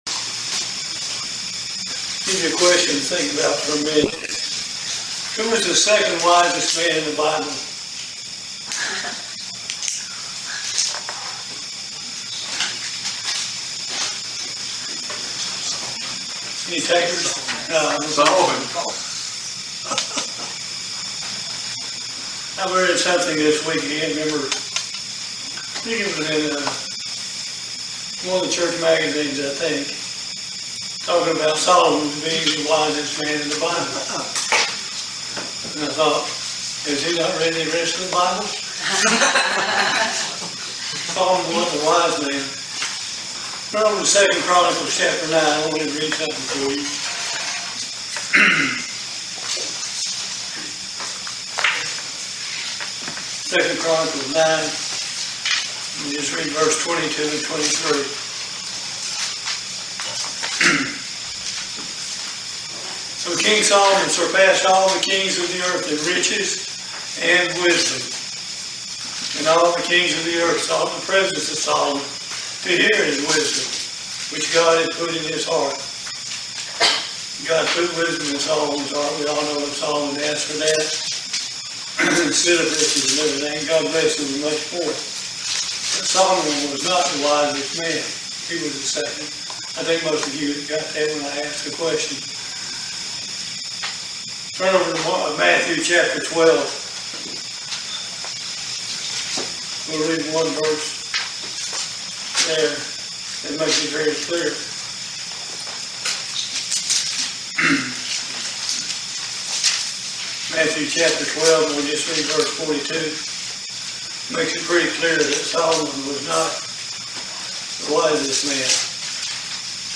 The answers to these questions and much more in this very interesting video sermon.